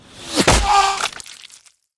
sniper-shot.mp3